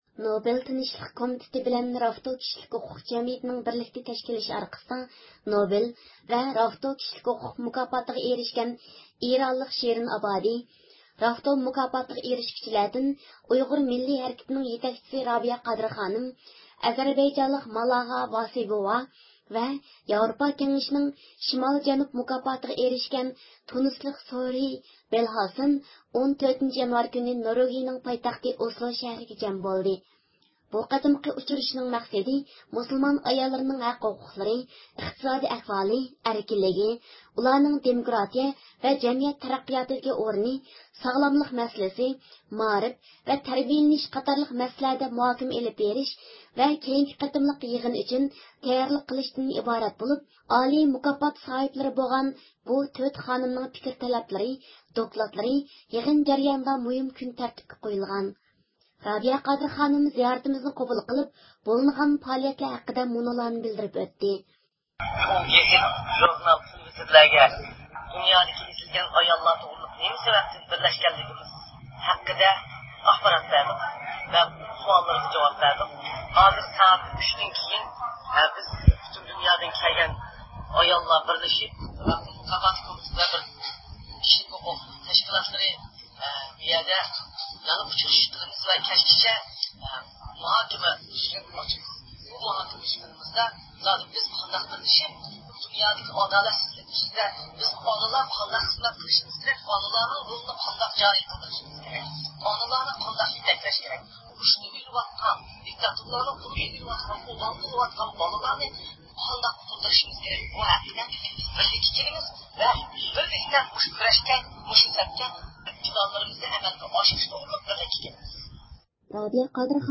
رابىيە خانىم زىيارىتىمىزنى قوبۇل قىلىپ بۇ پائالىيەتلەر ھەققىدە مۇنۇلارنى بىلدۈرۈپ ئۆتتى.